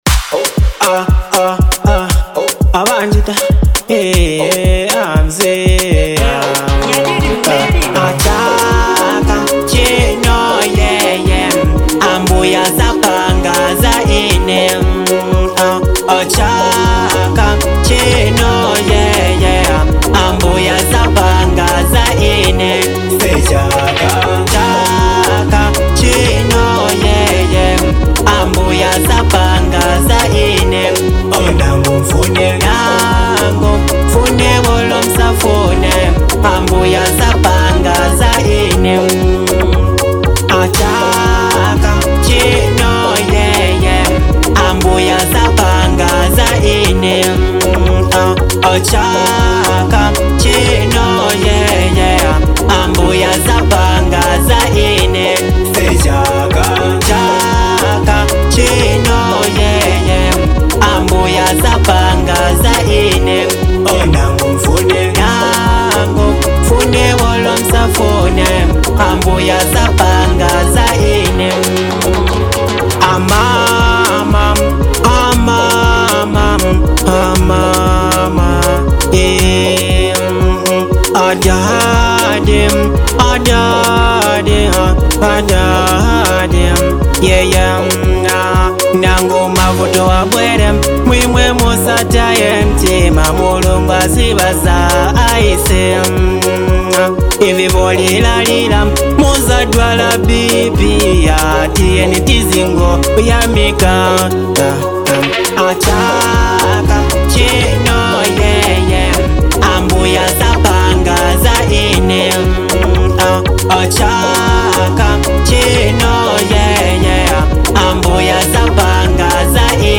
hot banger
a confident anthem